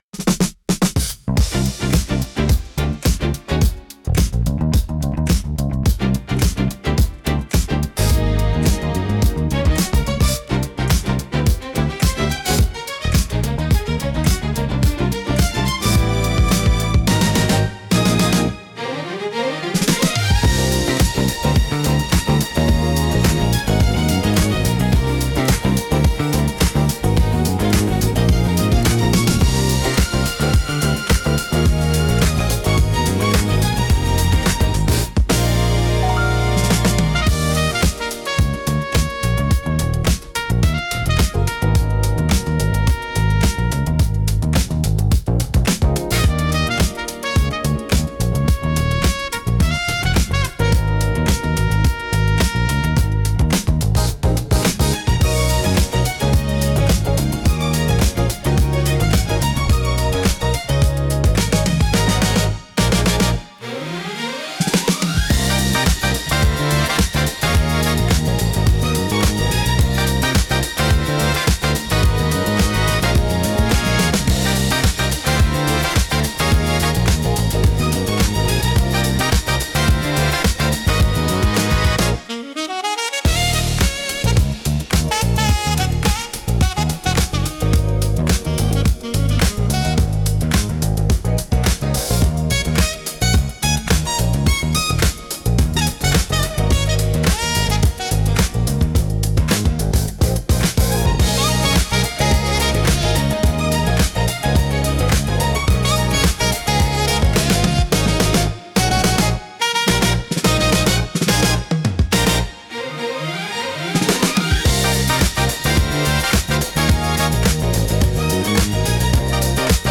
エレガントでリズミカルな演奏が心地よく、ダンスフロアからカフェシーンまで幅広く親しまれています。
落ち着きつつも躍動感があり、聴く人の気分を盛り上げつつリラックスさせる効果があります。